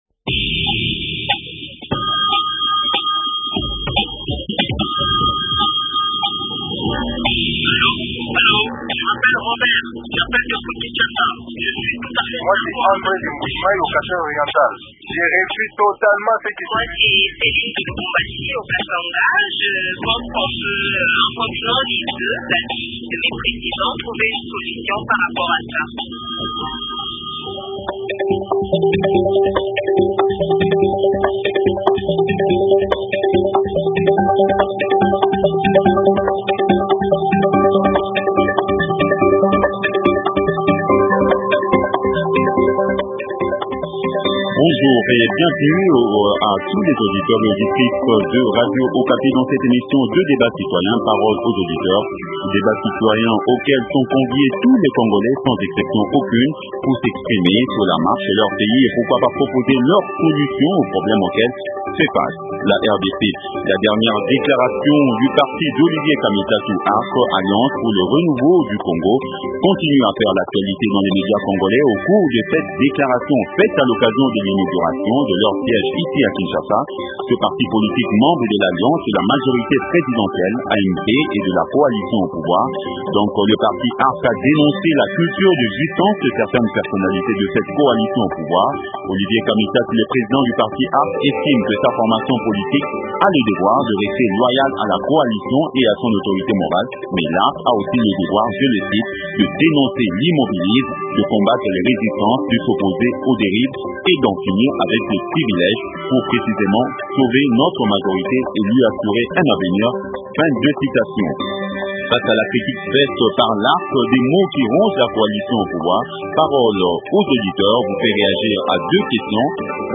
Invité: - Honorable Fabien Zoulou Kiloadi, député national et membre fondateur de l’ARCrn